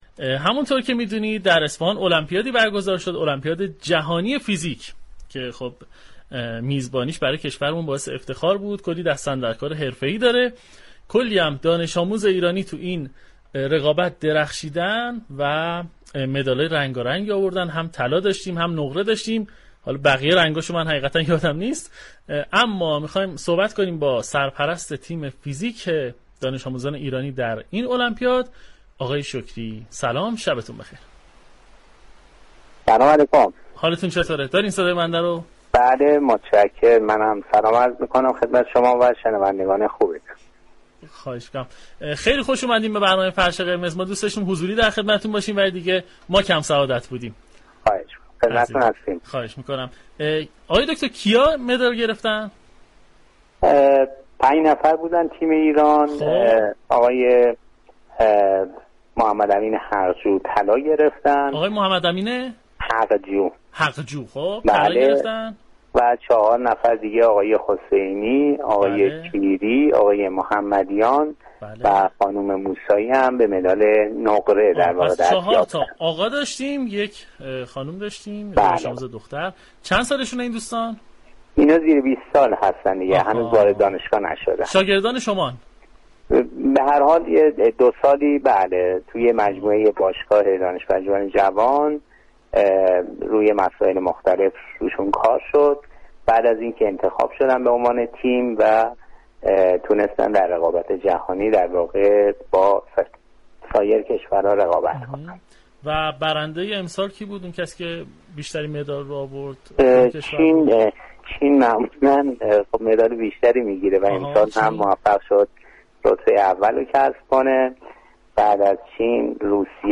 به گزارش روابط عمومی رادیو صبا، «فرش قرمز» عنوان یكی از برنامه‌های این شبكه رادیویی است كه در گفتگوی صمیمی میزبان نخبگان علمی كشور می‌شود.